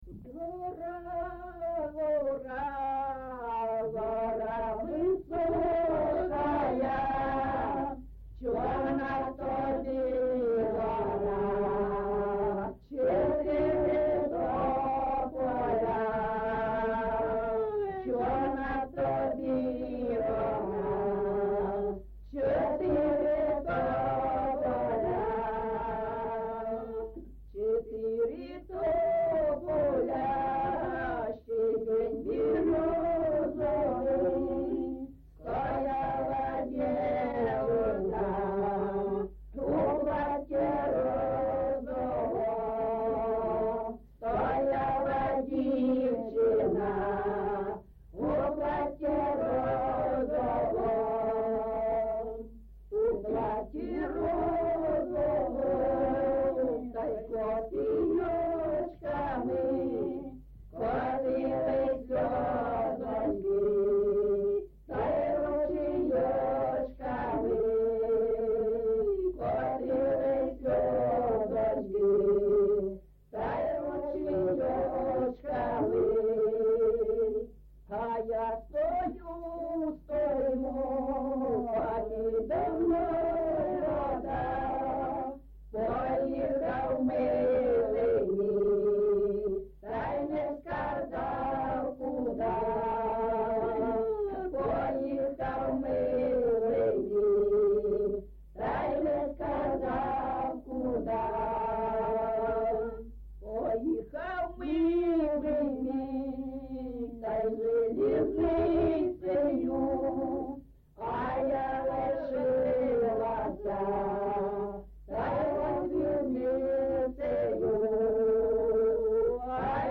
GenrePersonal and Family Life
Recording locationHalytsynivka, Pokrovskyi district, Donetsk obl., Ukraine, Sloboda Ukraine